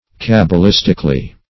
Cabalistically \Cab`a*lis"tic*al*ly\, adv.
cabalistically.mp3